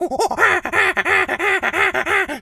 monkey_chatter_angry_11.wav